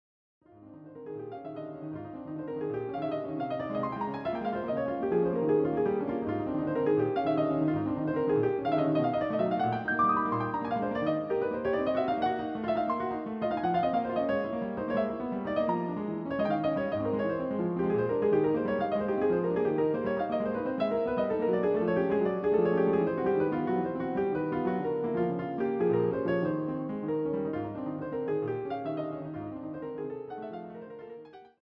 CD quality digital audio Mp3 file recorded
using the stereo sampled sound of a Yamaha Grand Piano.